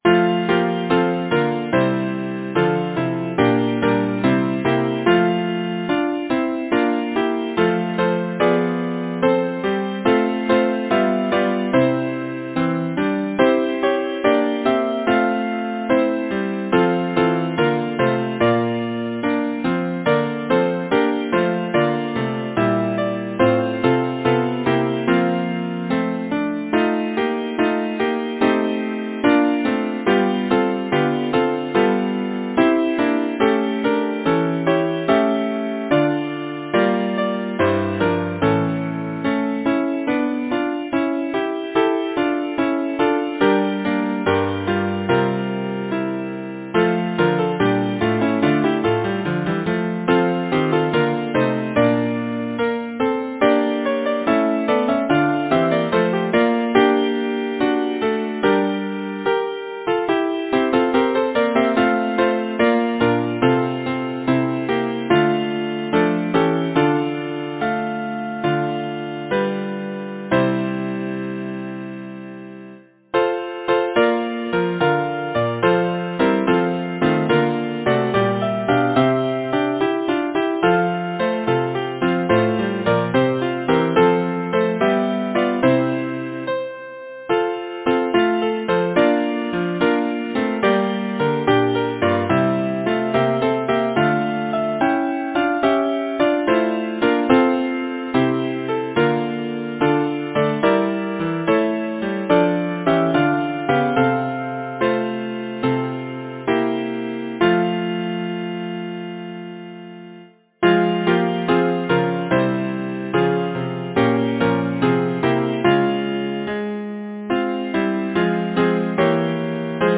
Title: The Lesson of the Leaves Composer: Alfred Alexander Lyricist: Isabella Varley Banks Number of voices: 4vv Voicing: SATB Genre: Secular, Partsong
Language: English Instruments: A cappella